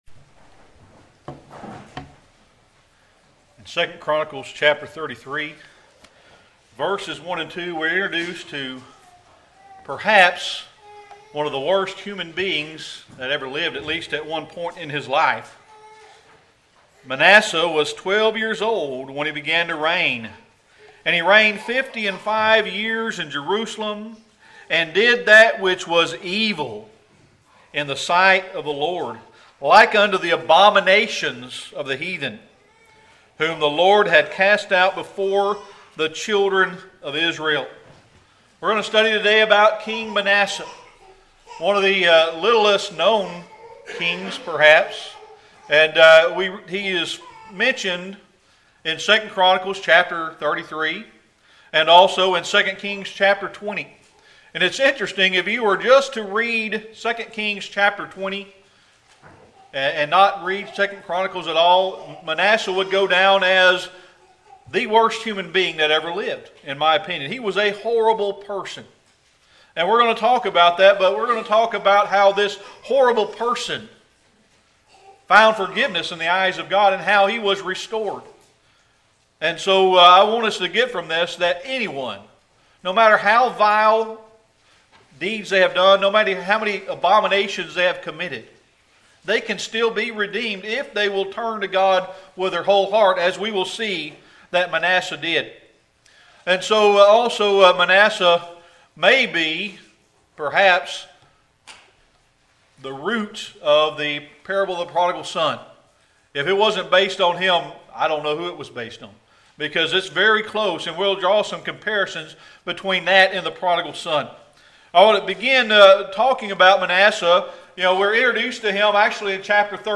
Series: Sermon Archives
2 Kings 21:1-18 Service Type: Sunday Morning Worship King Manasseh was a real life prodigal son.